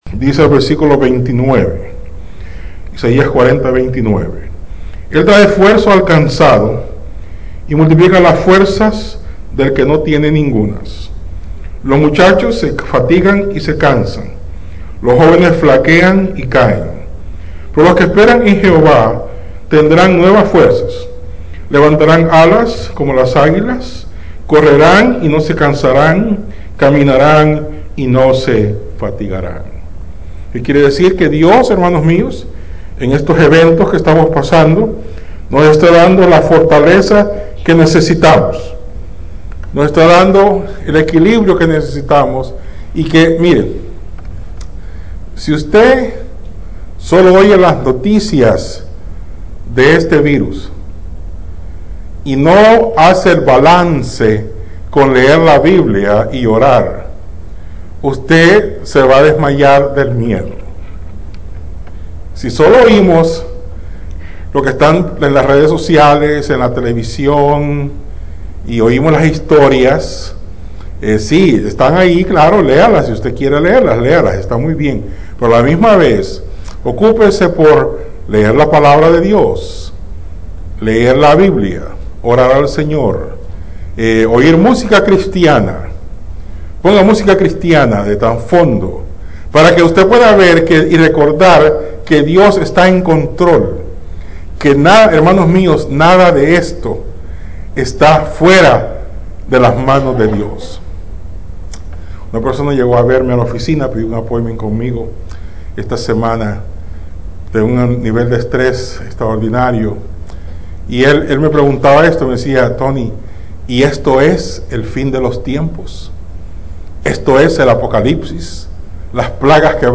Iglesia Bautista Central Sermones